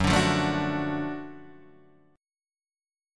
F#mM7#5 chord